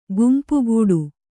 ♪ gumpugūḍu